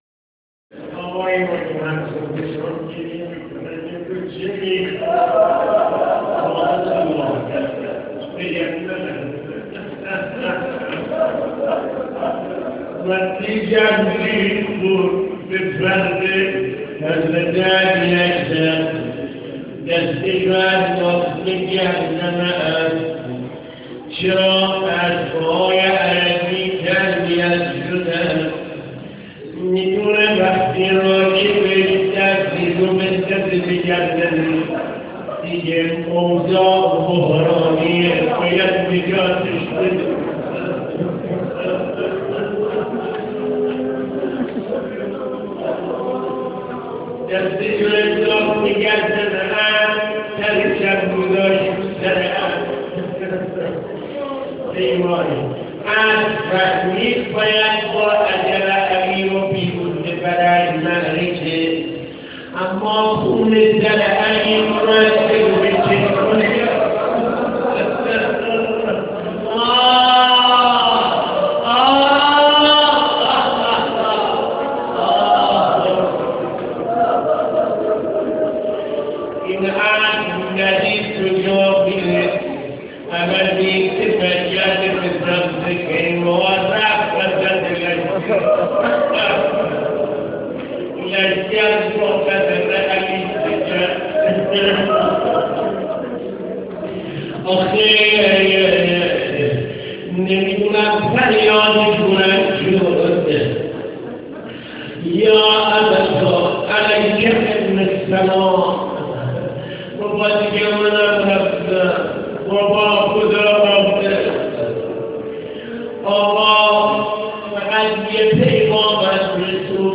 مقتل خوانی ظهر عاشورا مسجد هدایت تهران
مقتل خوانی ظهر عاشورا مسجد هدایت تهران متاسفانه مرورگر شما، قابیلت پخش فایل های صوتی تصویری را در قالب HTML5 دارا نمی باشد.